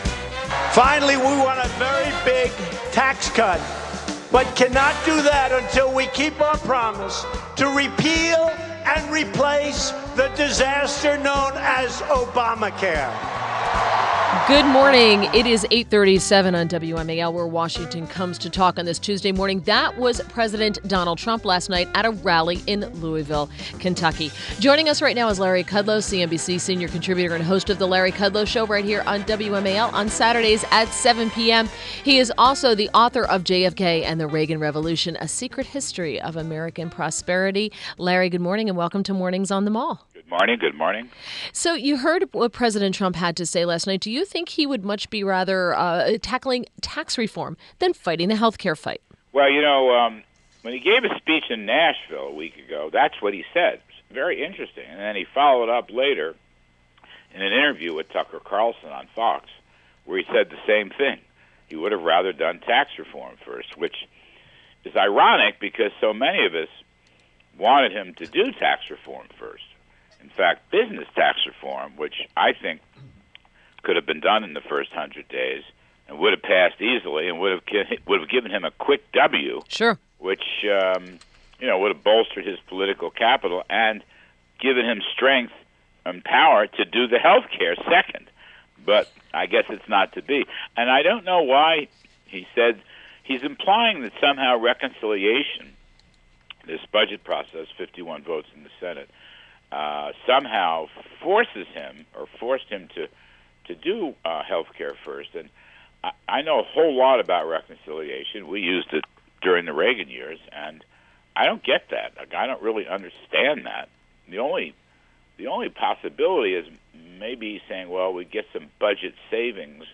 WMAL Interview - LARRY KUDLOW - 03.21.17